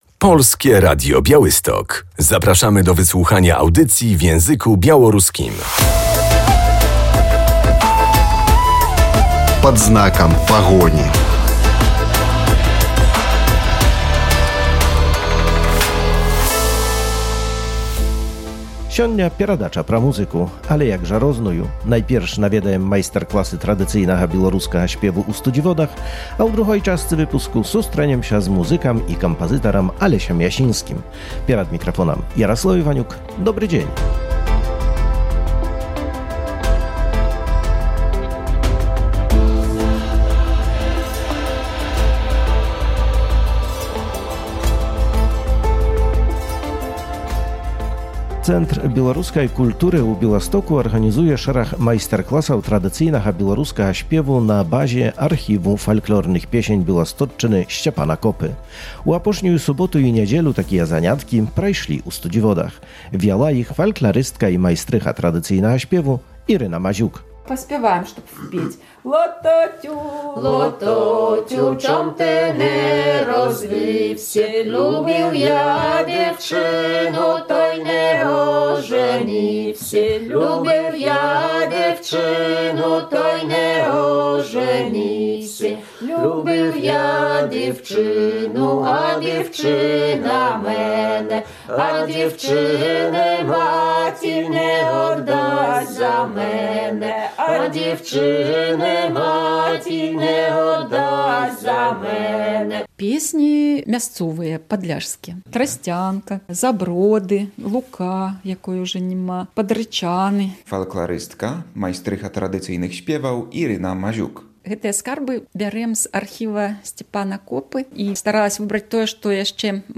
Śpiew tradycyjny ciągle w modzie 28.04.2024